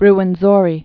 (rwən-zôrē)